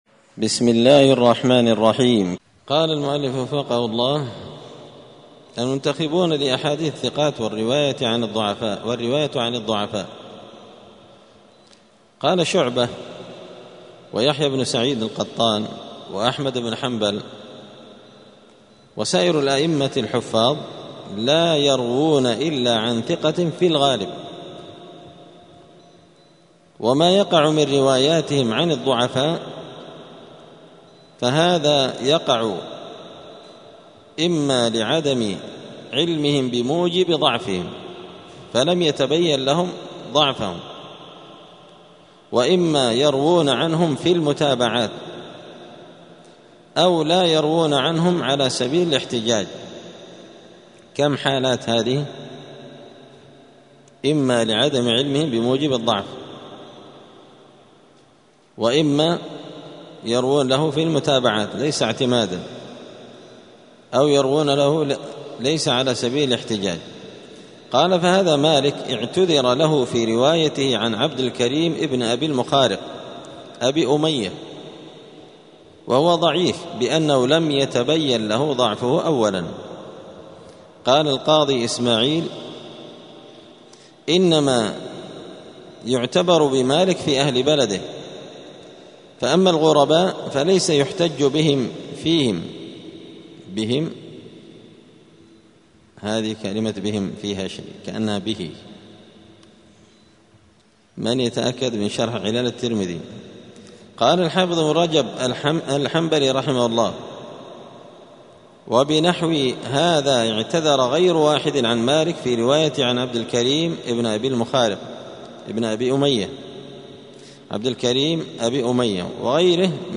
*الدرس الثاني والخمسون (52) المنتخبون لأحاديث الروايات.*